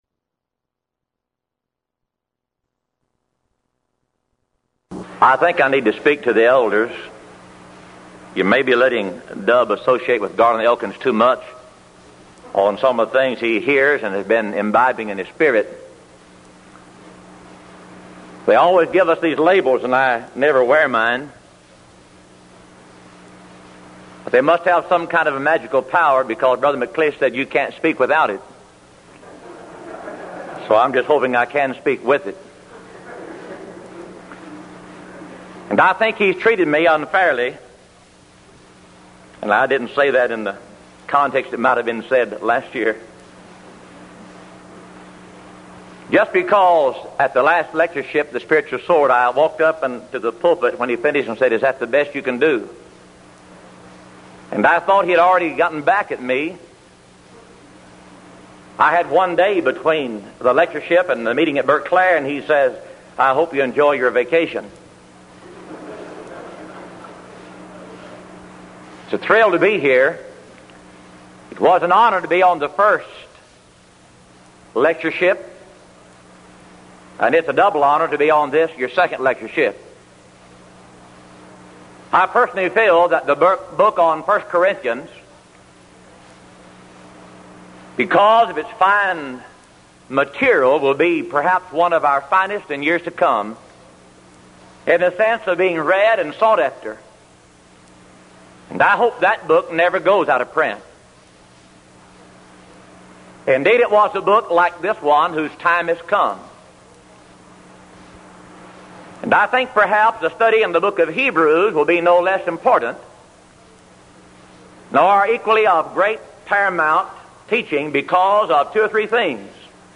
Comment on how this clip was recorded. Event: 1983 Denton Lectures